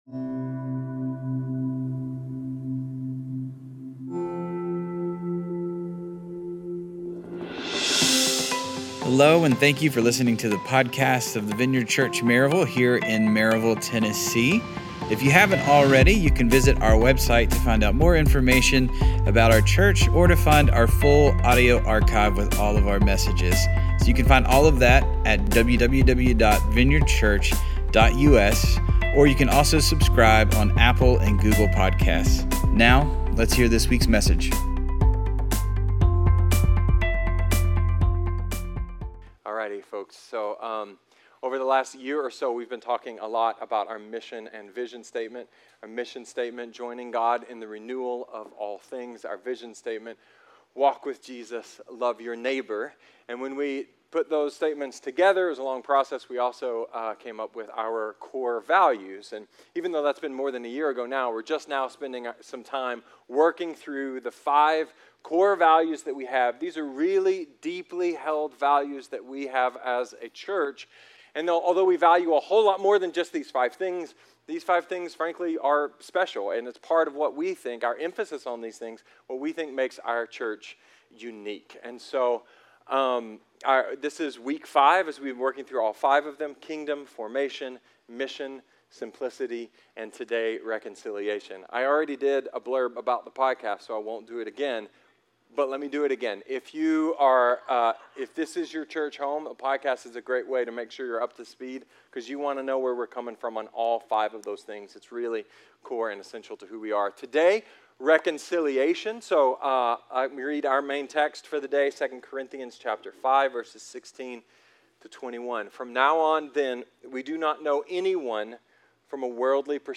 A sermon about healing relationships, and some hope for the relationships that don’t get healed.